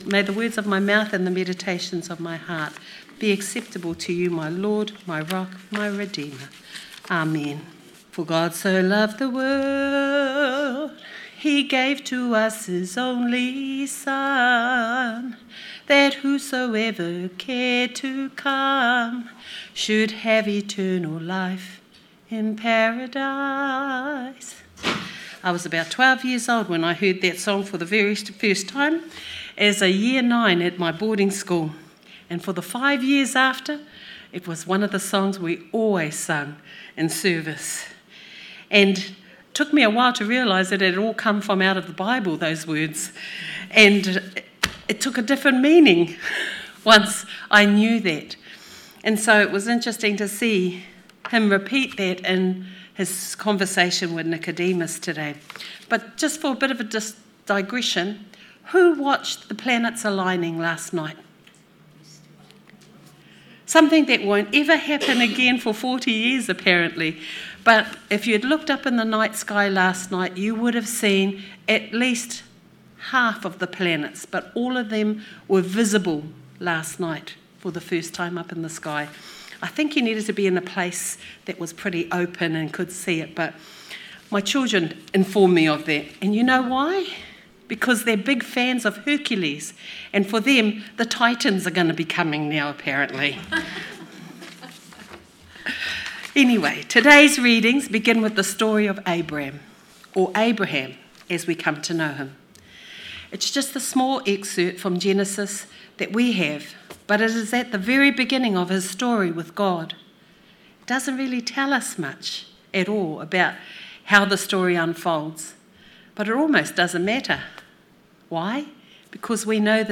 Sermon 1st March 2026